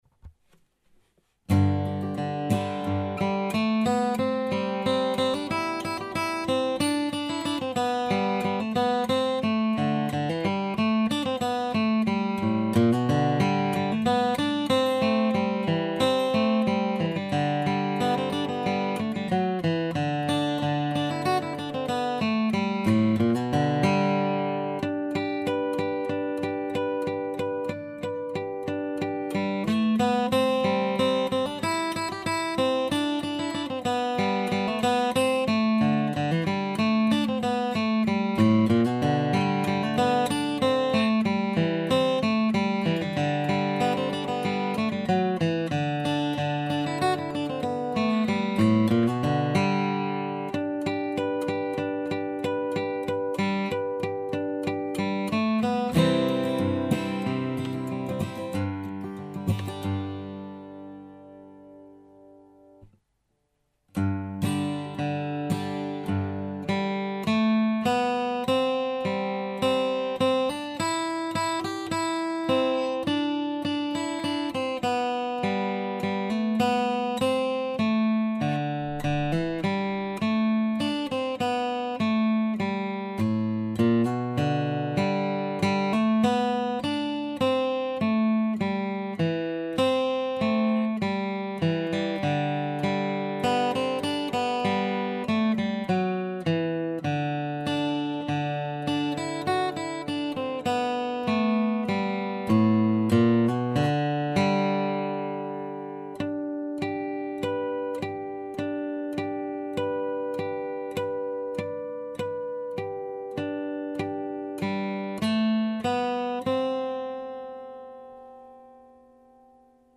v G Dur